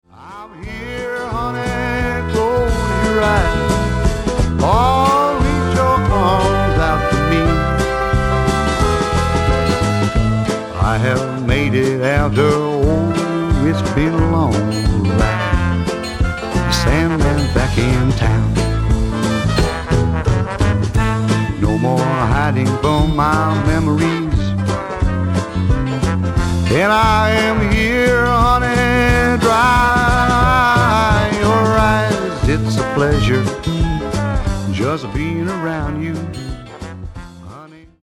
SSW / SWAMP ROCK